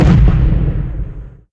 Expl03.wav